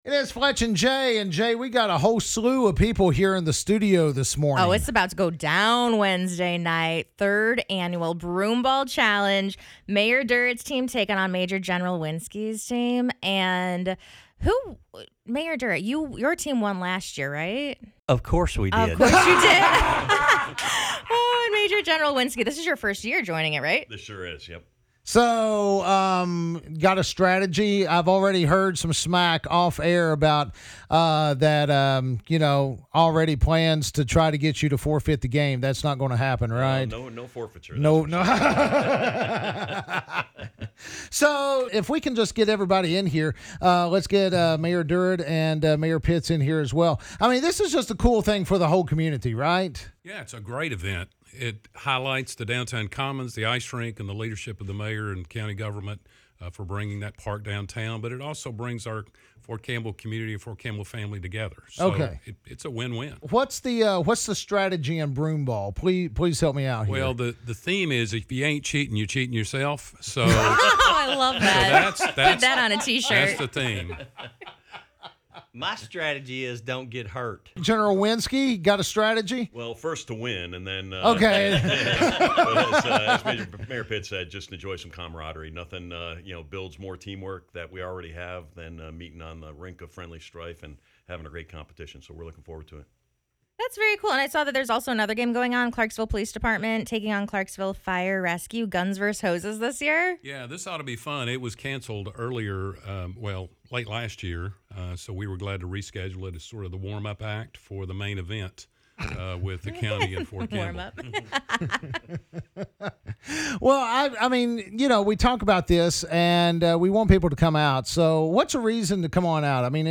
for a little friendly banter on just who will be taking home this year’s title of Broomball Champions!!